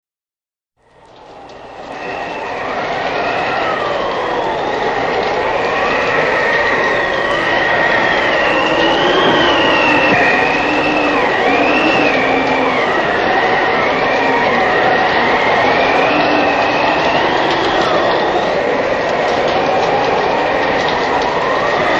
Громовые раскаты, завывание ветра и шум ливня создают эффект присутствия.
Шум снежной бури